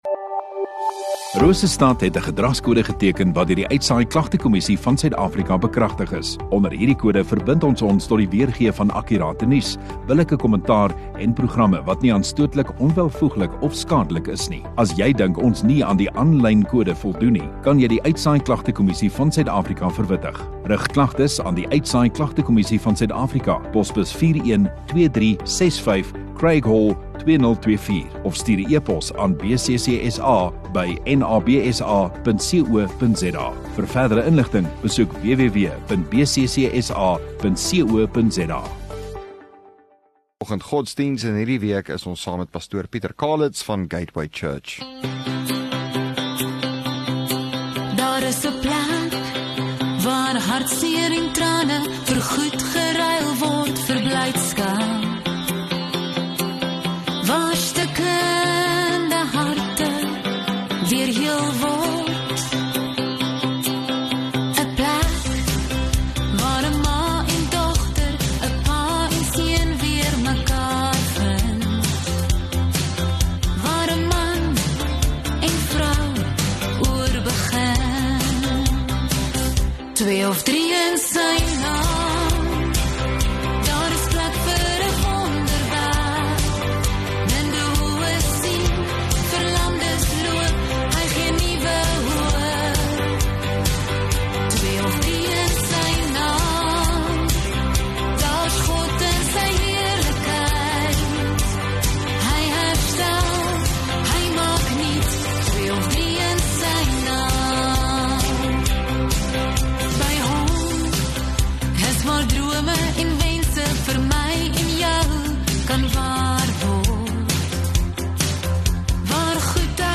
4 Apr Vrydag Oggenddiens